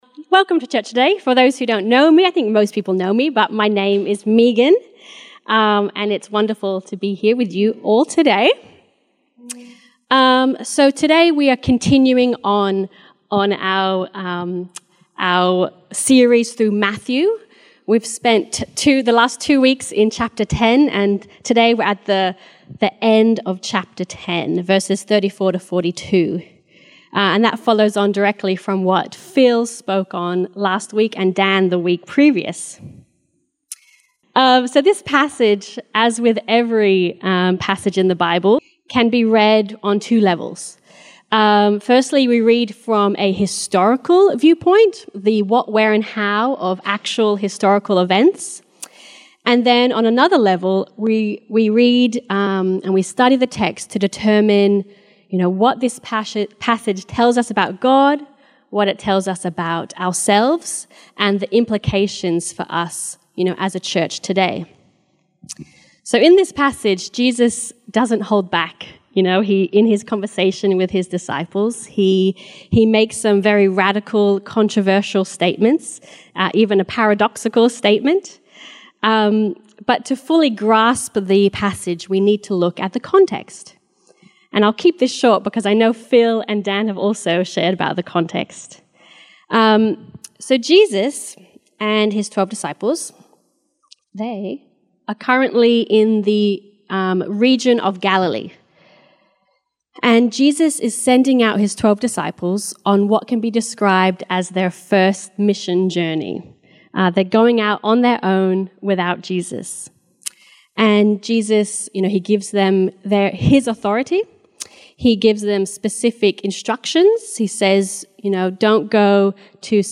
Summerhill Baptist Church Sermons